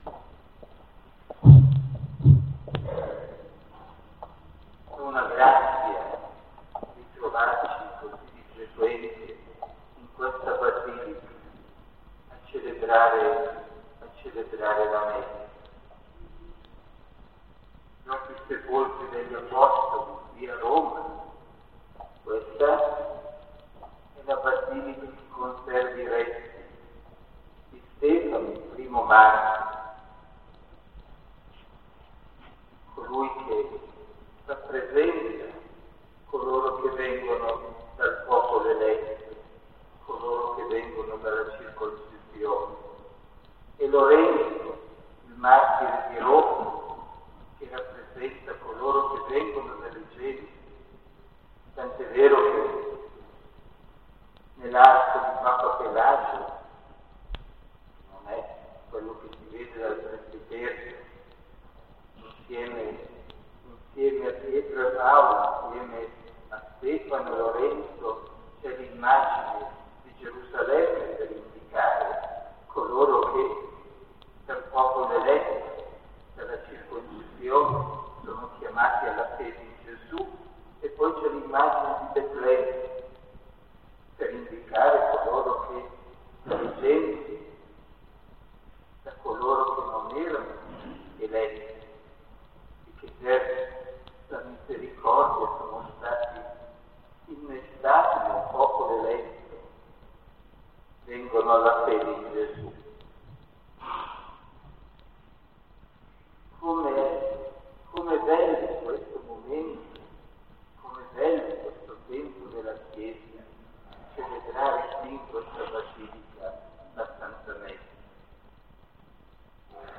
OMELIA Santo Stefano